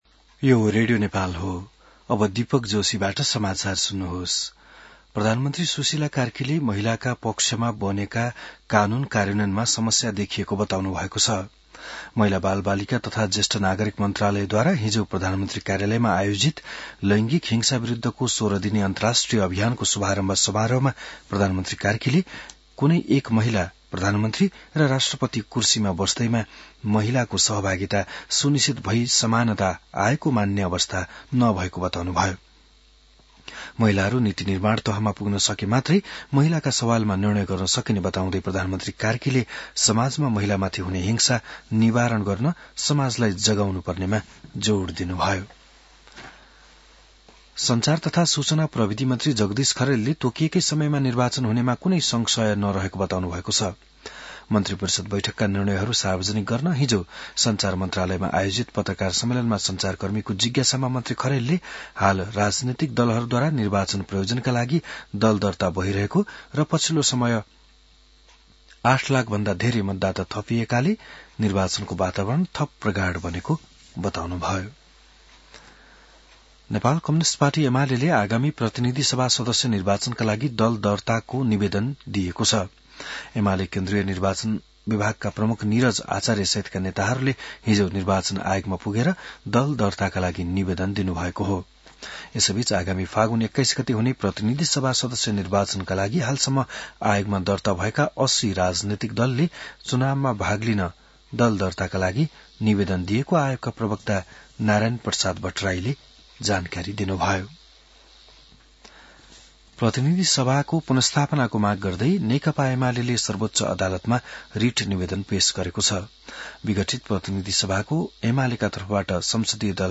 बिहान १० बजेको नेपाली समाचार : १० मंसिर , २०८२